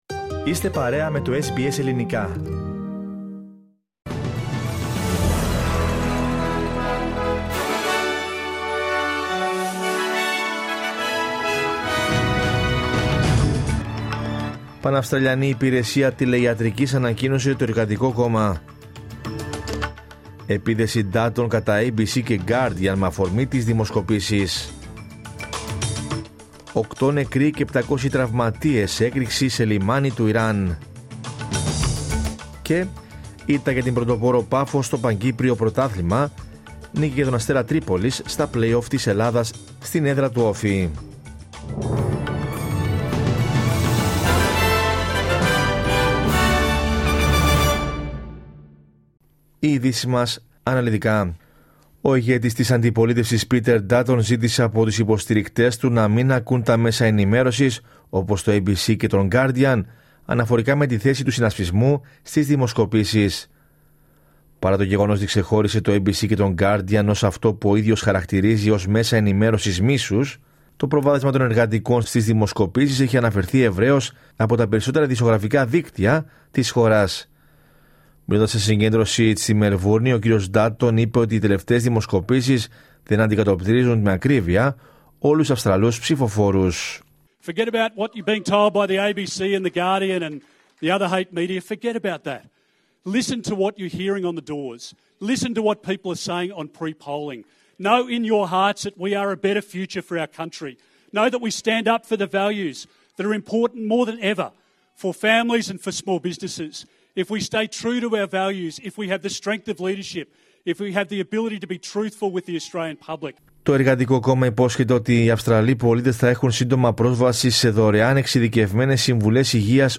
Δελτίο Ειδήσεων Κυριακή 27 Απριλίου 2025